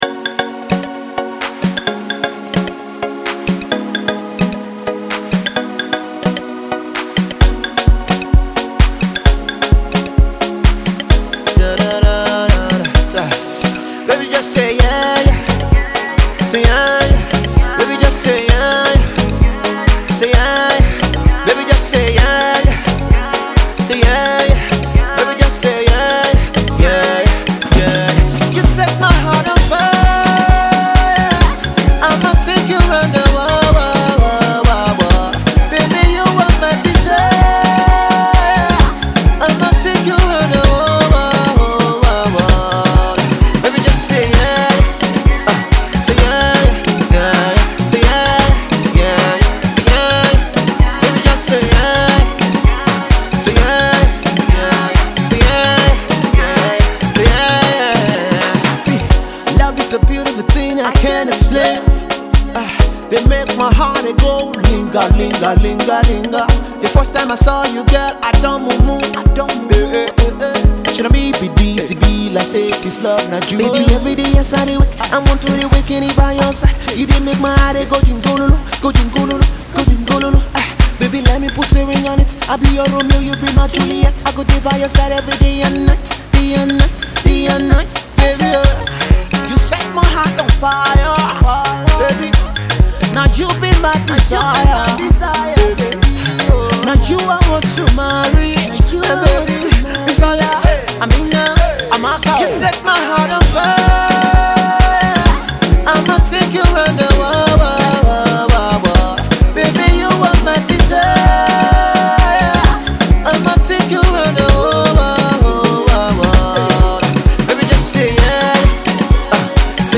Up and Coming Rap Artiste